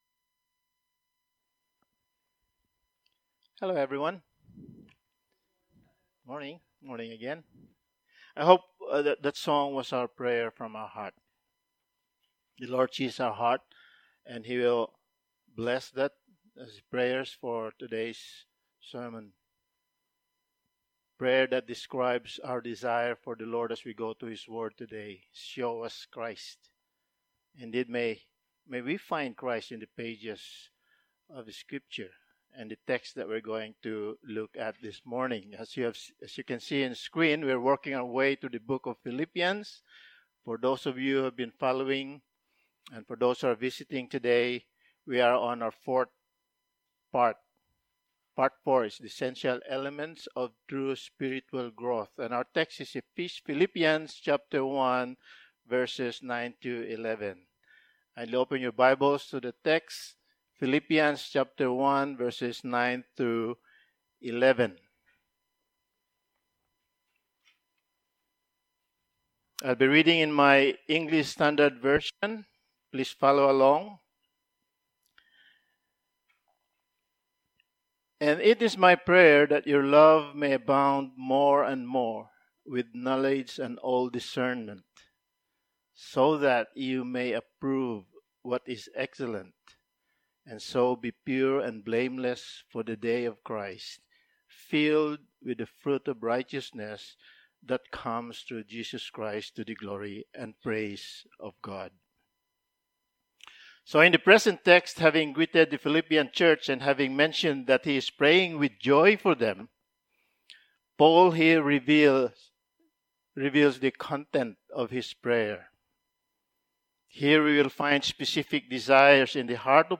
Passage: Philippians 1:9-11 Service Type: Sunday Morning